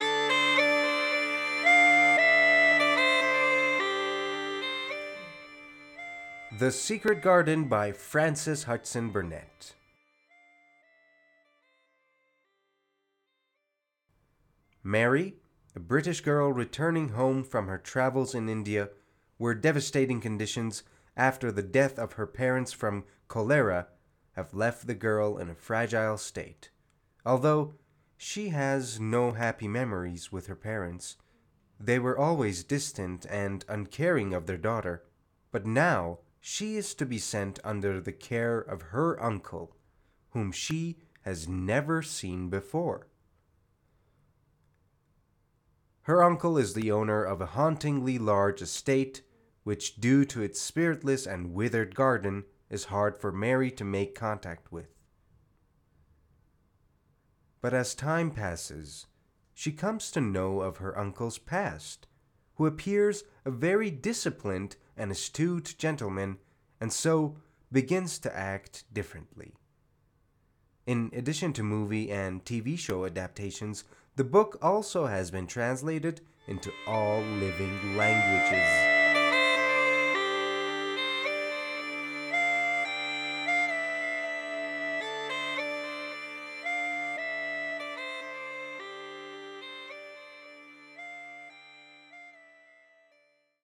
معرفی صوتی کتاب The Secret Garden